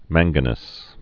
(mănggə-nəs)